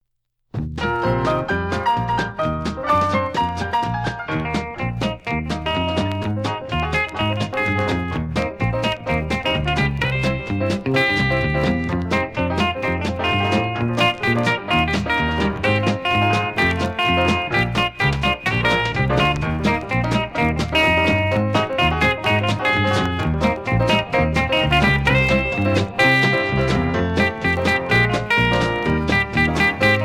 Singing Call